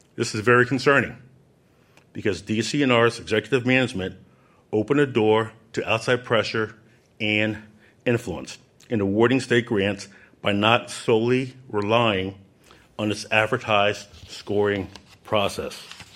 During a news conference on Thursday, Auditor General Timothy DeFoor stated that DCNR officials surrendered to outside pressure when awarding grant money, even making decisions with legislative consequences in mind.